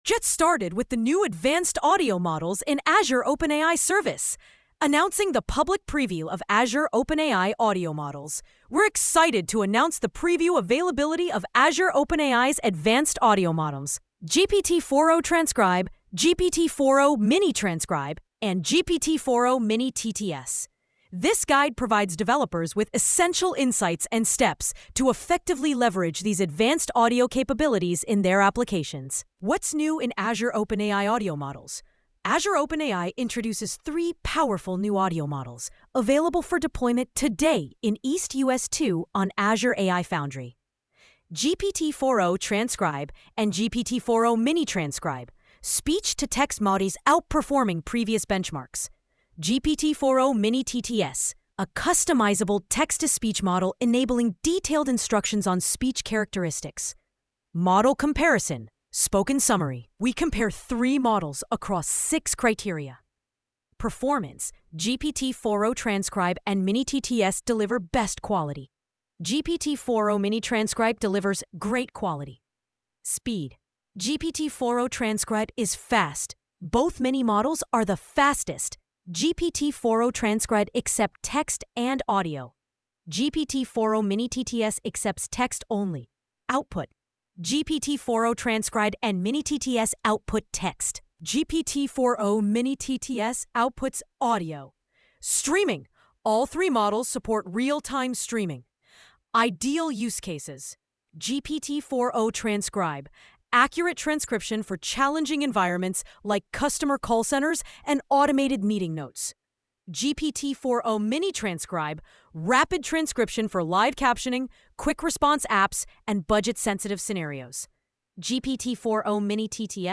• GPT-4o-Mini-TTS: A customizable text-to-speech model enabling detailed instructions on speech characteristics.
aoai-audio-tts-blog-sample.mp3